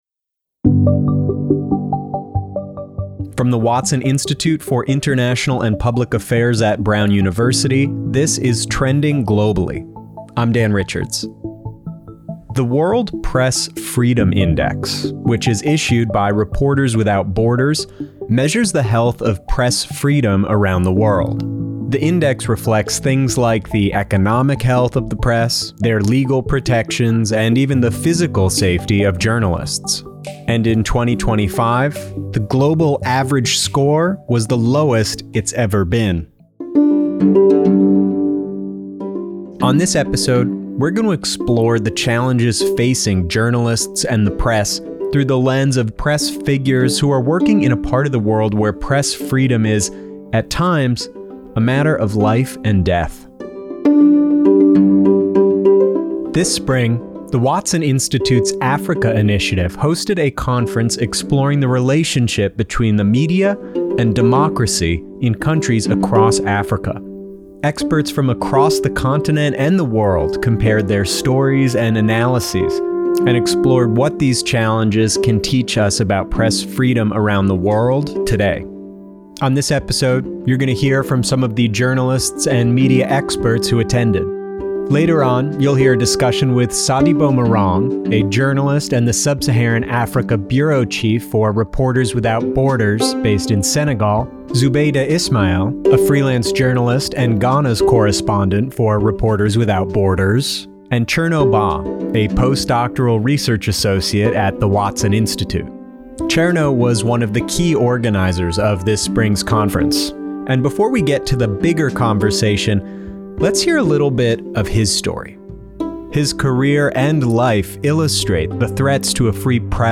These guests, along with many others, gathered at the Watson Institute this Spring as part of the Media and Democracy Conference hosted by Watson’s Africa Initiative.